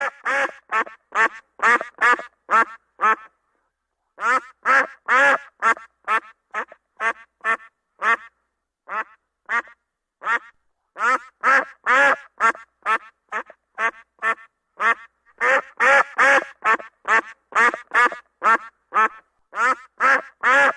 Ente klingelton kostenlos
Kategorien: Tierstimmen
ente.mp3